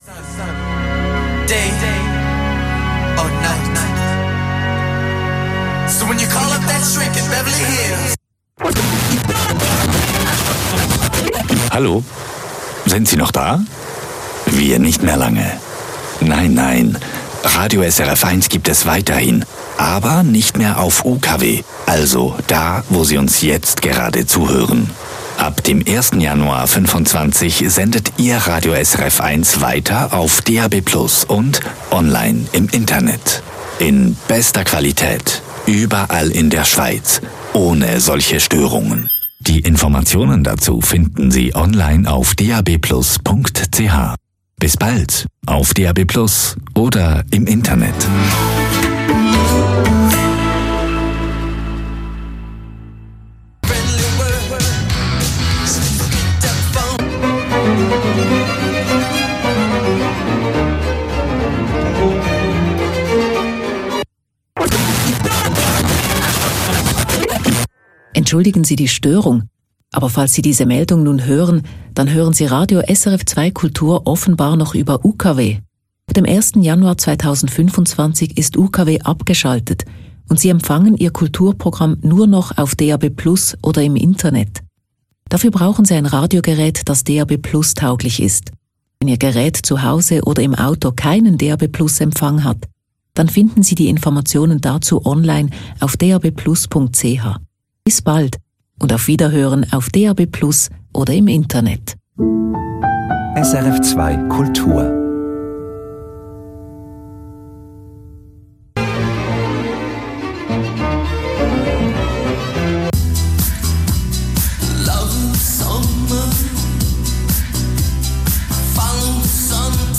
Twee keer op een dag (niet ’s nachts) is op de radiostations een boodschap te horen waarin gewezen wordt op de op stapel staande afschakeling van FM. De spotjes worden op willekeurige momenten uitgezonden, dus ook middenin de muziek of gesproken woord van de presentator of deejay.
Melding-afschakeling-FM-Zwitserland.mp3